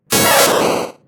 pikachu_ambient.ogg